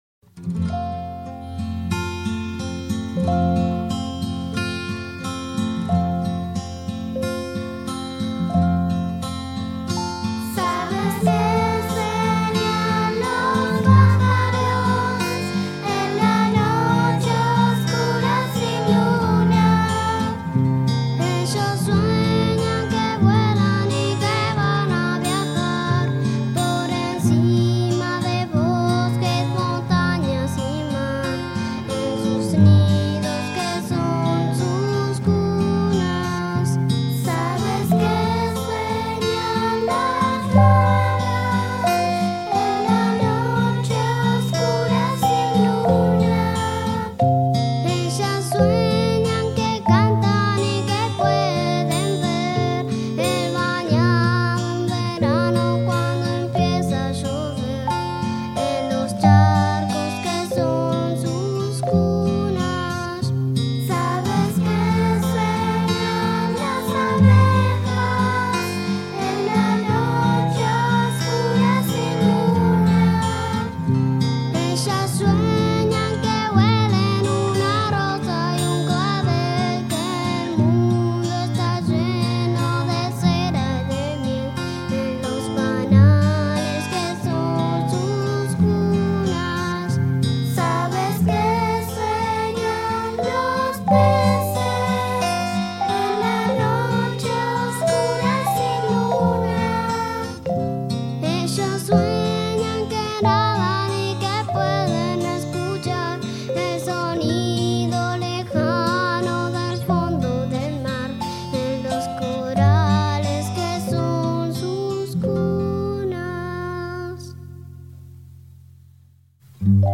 Paradormir
canción de cuna
oboe